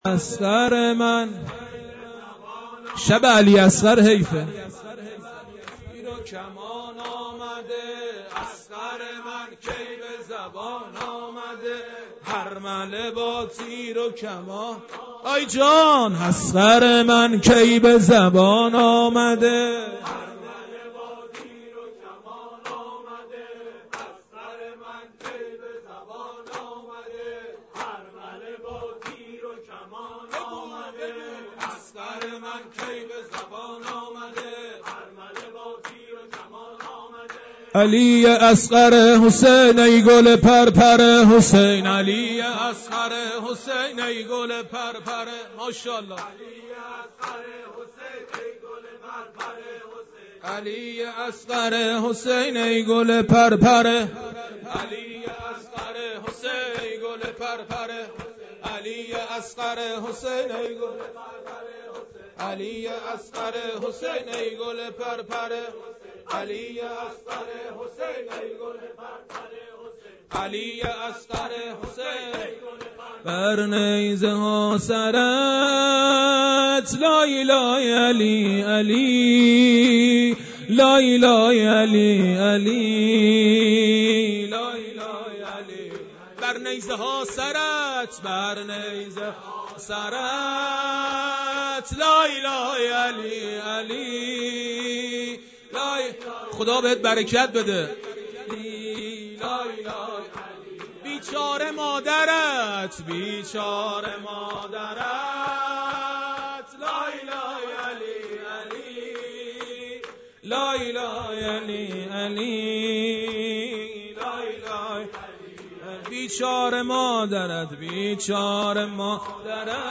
خیمه گاه - هیئت حضرت رقیه س (نازی آباد) - شب هفتم مداحی